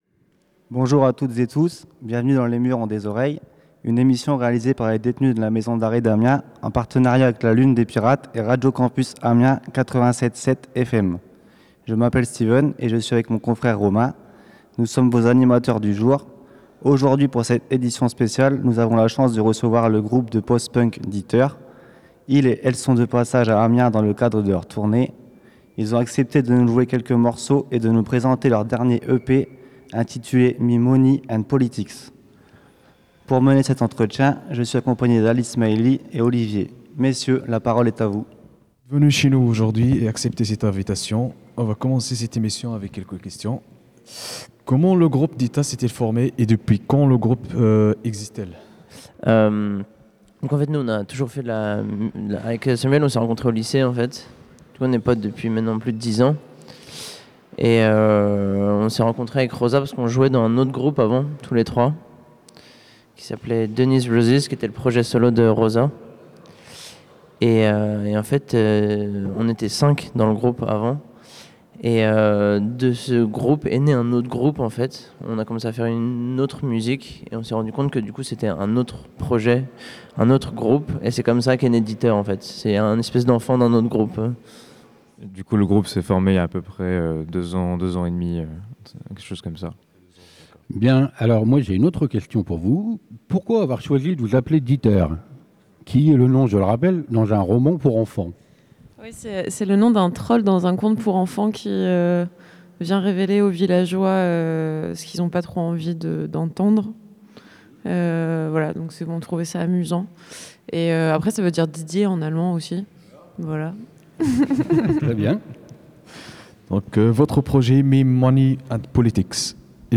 Inspirée des "Bruits de Lune" (émission de radio enregistrée en direct de La Lune un mercredi par mois en partenariat avec Radio Campus), cette initiative a permis aux participants d’explorer la création sonore et le journalisme radio. L’expérience s'est conclu par une rencontre avec le groupe DITTER, suivie d’un concert réunissant une quarantaine de détenus, le tout enregistré et disponible à l'écoute sur le site de Radio Campus Amiens .